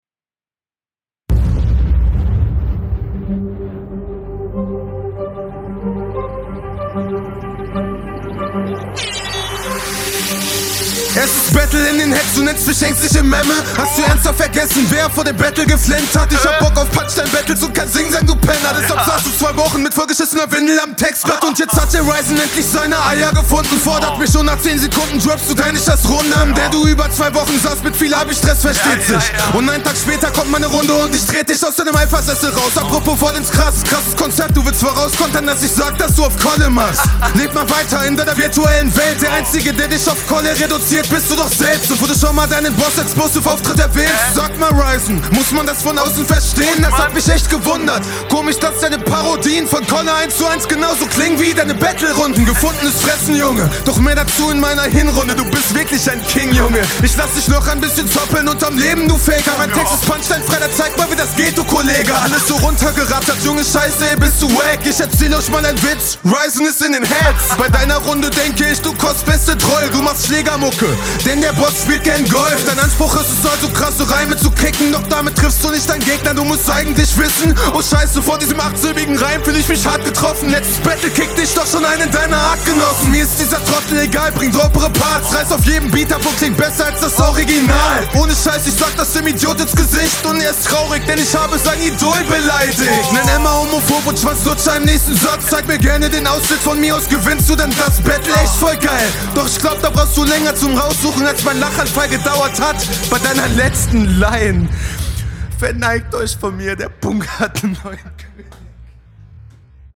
ooo, du kommst gleich mal sehr mächtig auf den beat. bisi vernuschelt an manchen stellen …
Du bis stimmlich schon eher da, wo ich das auf diesen Beat selbst ansiedeln würde!